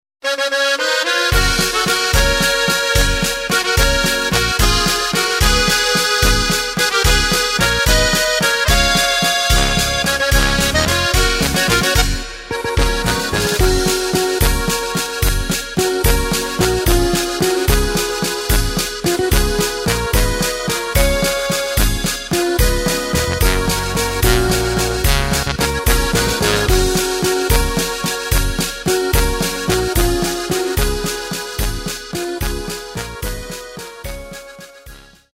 Takt:          3/4
Tempo:         220.00
Tonart:            F#
Walzer aus dem Jahr 2010!